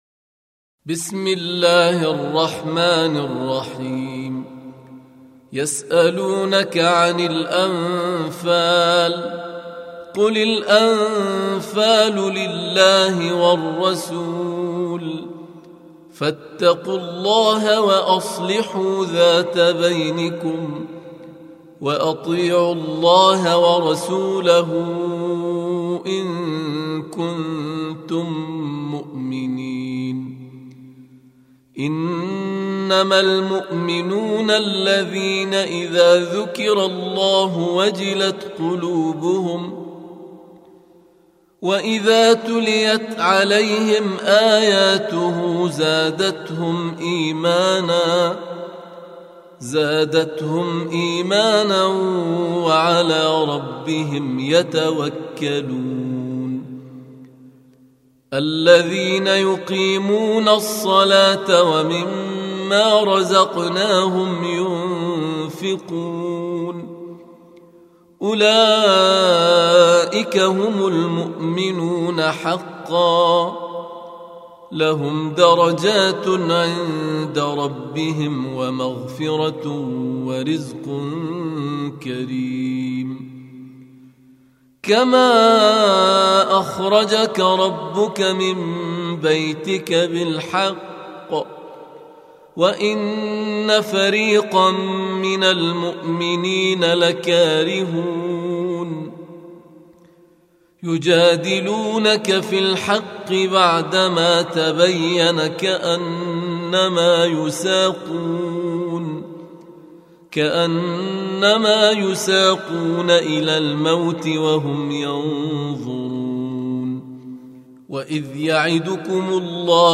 8. Surah Al-Anf�l سورة الأنفال Audio Quran Tarteel Recitation
Surah Sequence تتابع السورة Download Surah حمّل السورة Reciting Murattalah Audio for 8.